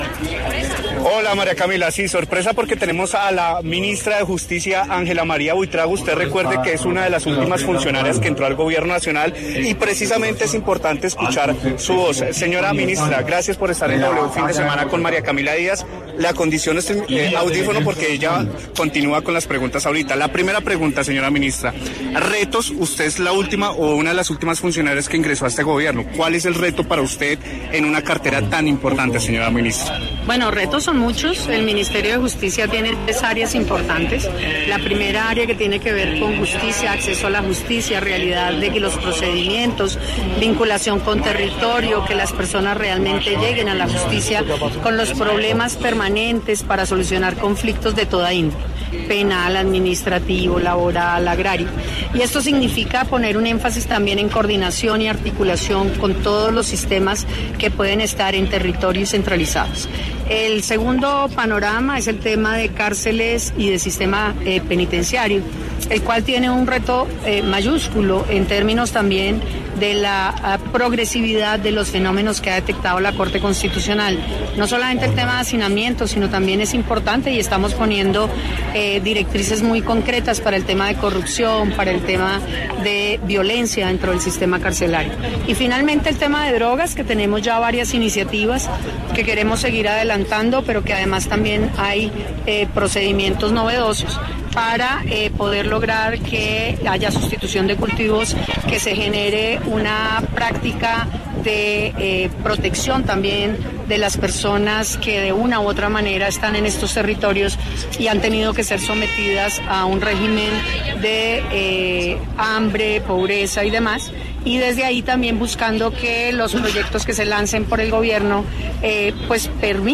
Así, Buitrago llegó a una entidad con retos considerables y por esta razón pasó por los micrófonos de La W para analizarlos.